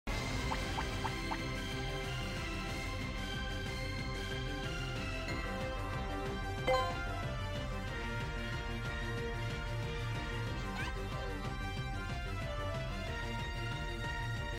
Pikachu con fondo del estadio sound effects free download
Pikachu con fondo del estadio de los Mets de New York